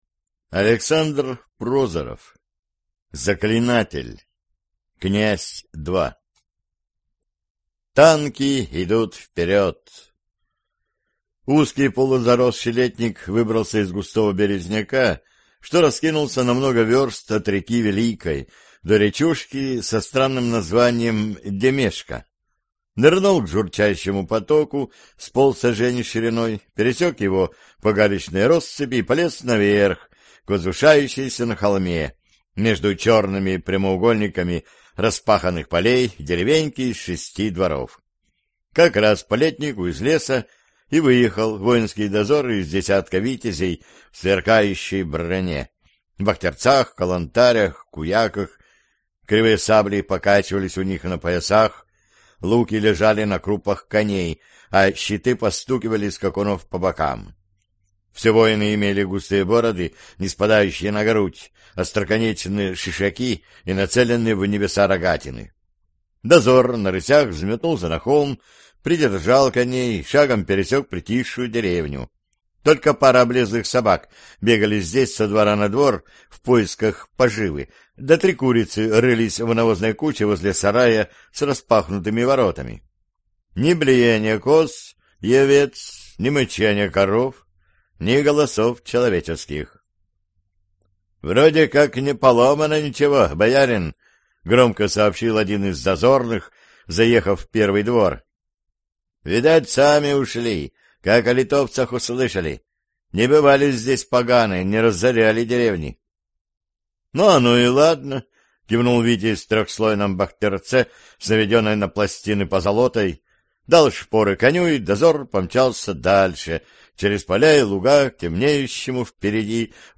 Аудиокнига Заклинатель | Библиотека аудиокниг